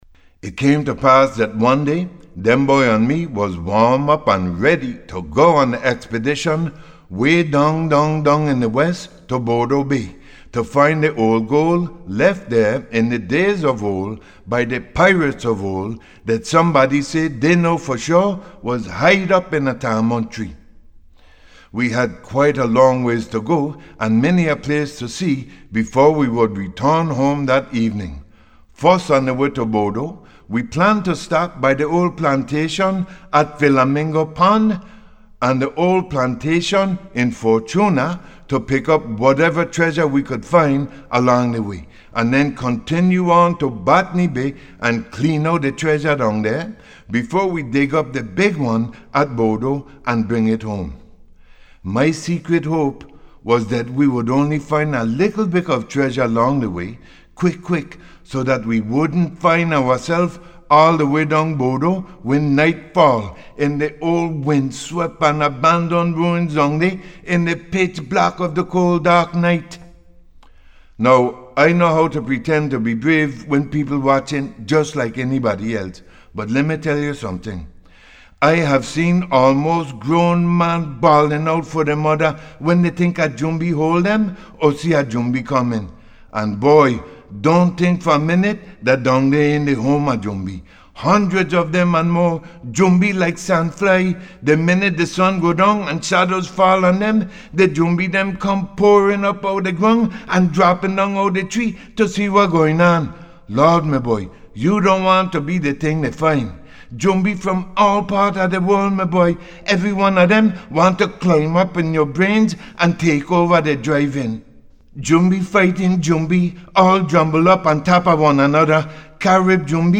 (A Piece of Spoken Calypso Comedy from my new Musical “The Virgin Islands Songs”. I Hope that you are able to understand and enjoy it)